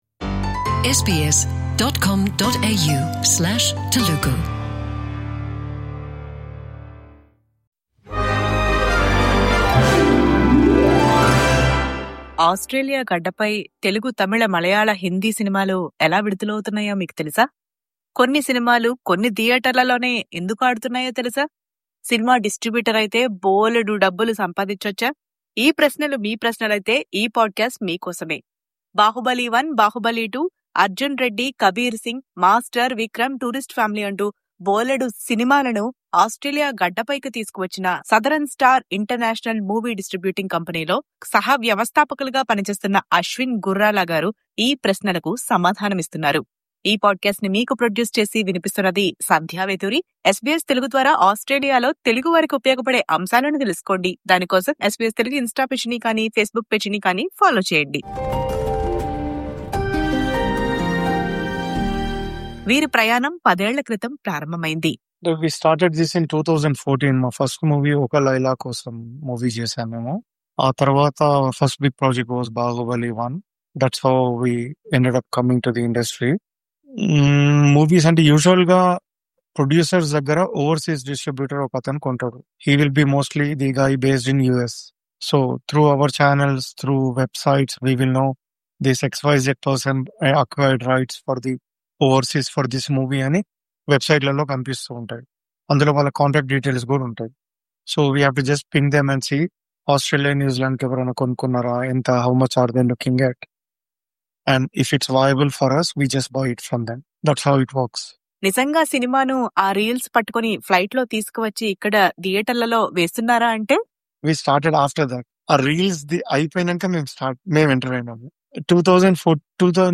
ప్రత్యేక ఇంటర్వ్యూ